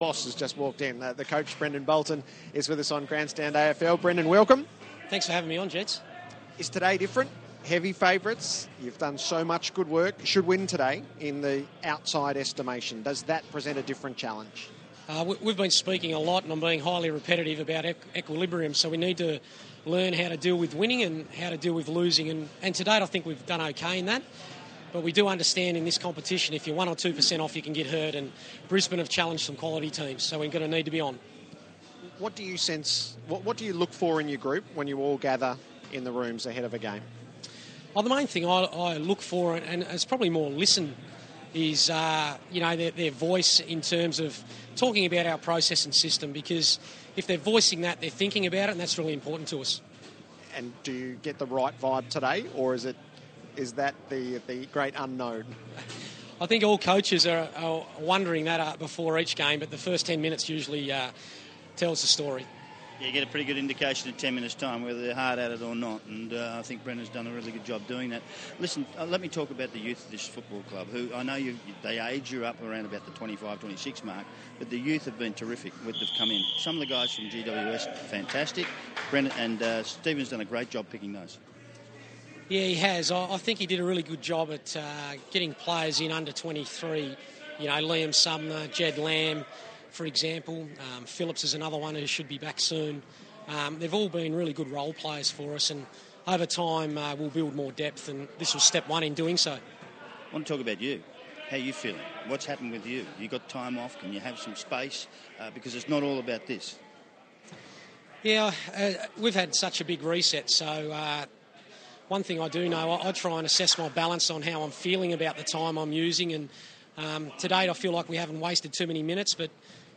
Carlton coach Brendon Bolton speaks with ABC AFL's Mark Maclure and Gerard Whateley in the rooms ahead of the Blues' clash against Brisbane.